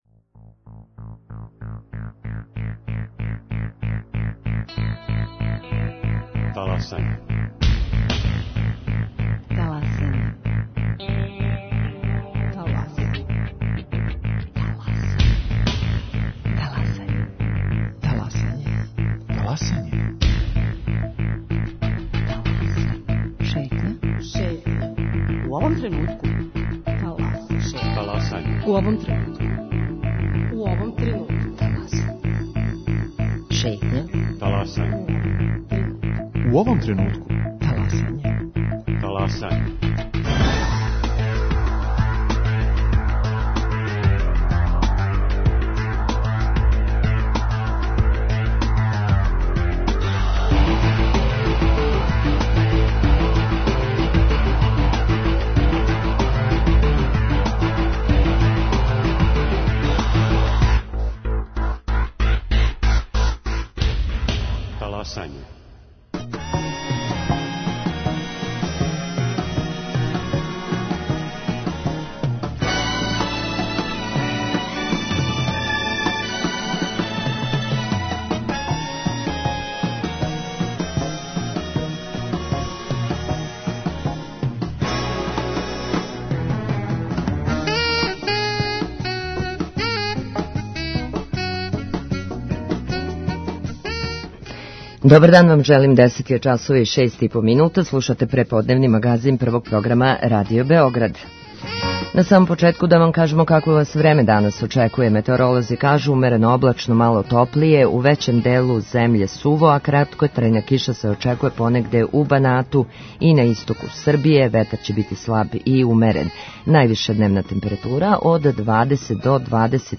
У другом сату ћете чути репортажу о Призрену који је био највредније и најзначајније градско насеље на Косову и Метохији.